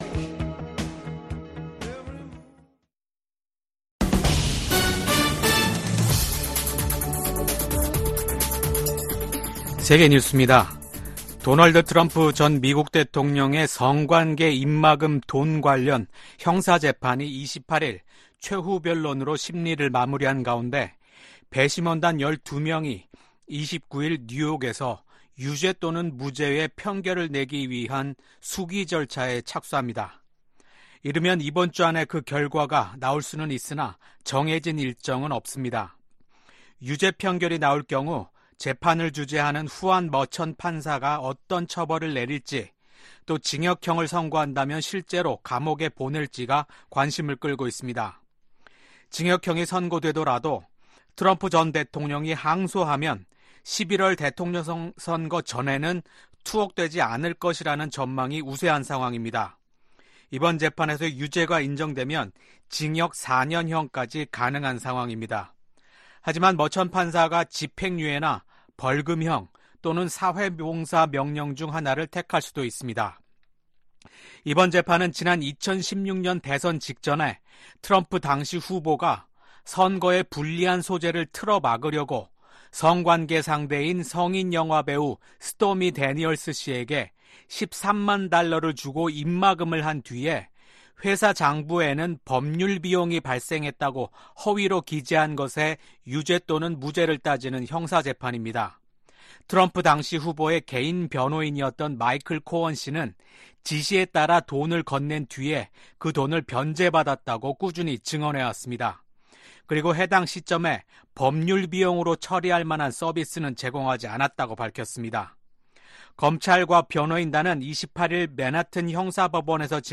VOA 한국어 아침 뉴스 프로그램 '워싱턴 뉴스 광장' 2024년 5월 30일 방송입니다. 미 국무부는 최근의 한일중 정상회담과 관련해 북한 문제에 대한 중국의 역할이 중요하다는 점을 거듭 강조했습니다. 전 세계 주요국과 국제기구들이 계속되는 북한의 미사일 발사는 관련 안보리 결의에 대한 명백한 위반이라고 비판했습니다.